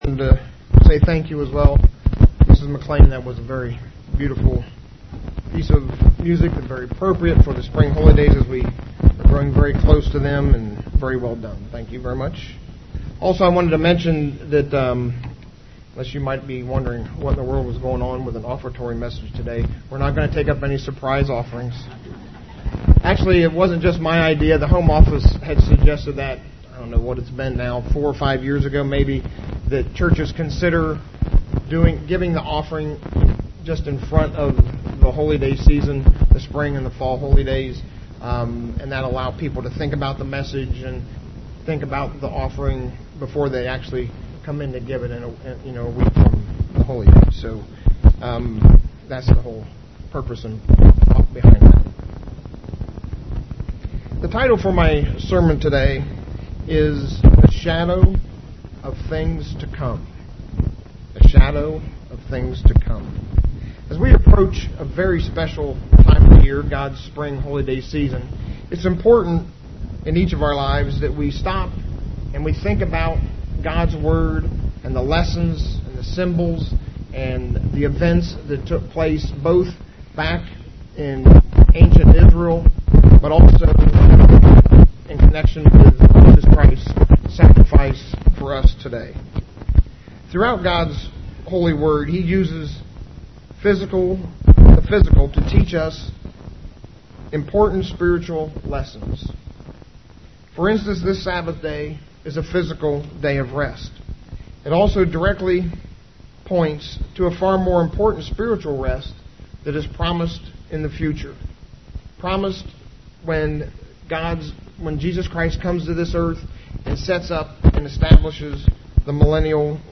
UCG Sermon Notes Notes: God uses the physical to point to the spiritual reality of things to come.